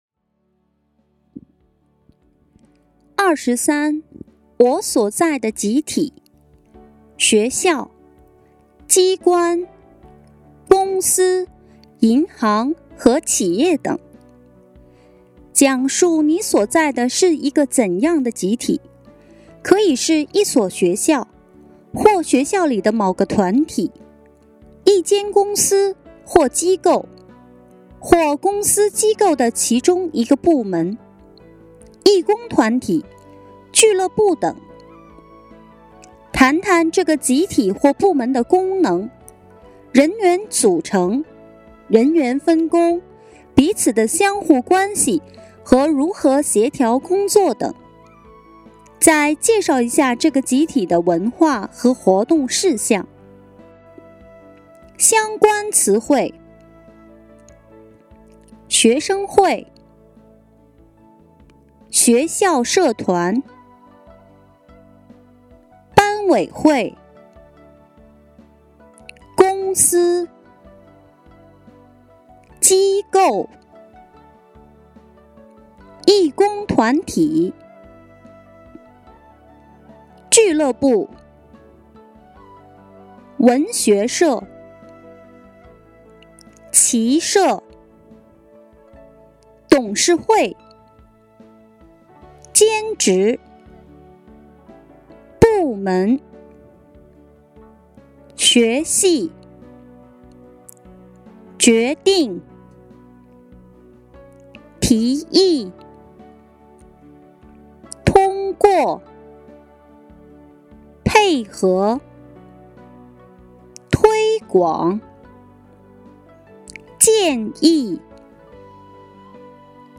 第二十三題 《我喜歡的節日我所在的集體（學校、機關、公司、銀行和企業等）》語音參考